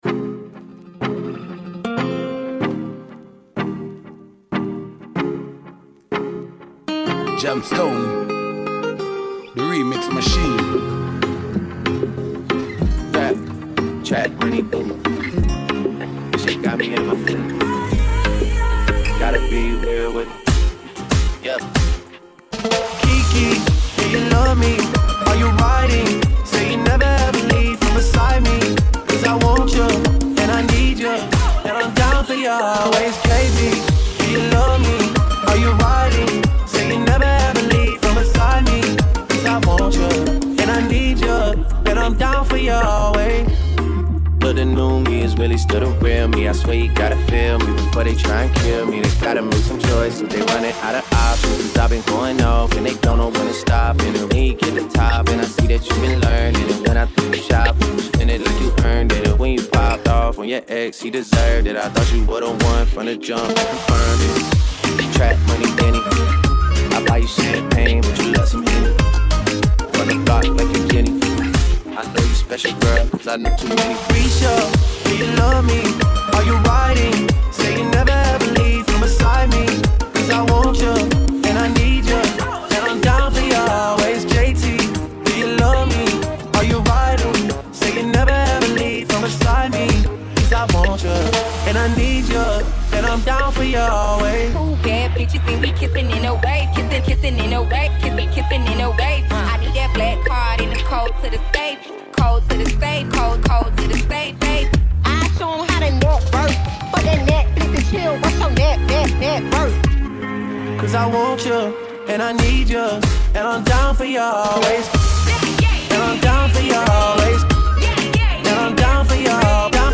Vocal track
Reggaeton